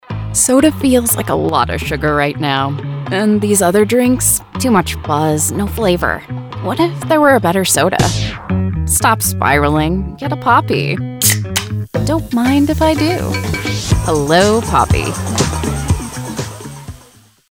BEVERAGE COMMERCIAL- Conversational, Real, Youthful, Fun